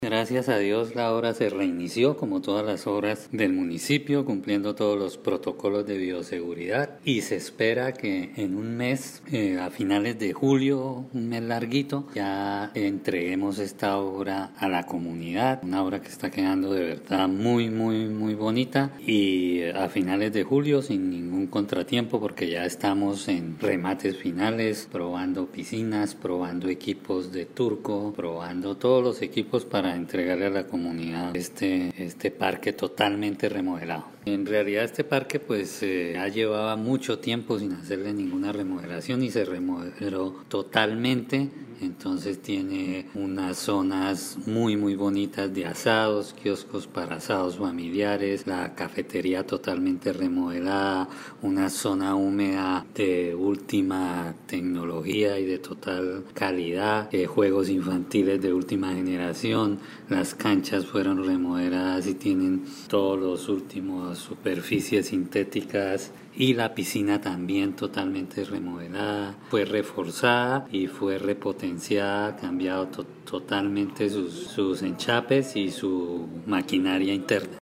Iván José Vargas, secretario de Infraestructura del Municipio
Iván-José-Vargas-Parque-Recrear-del-Norte.mp3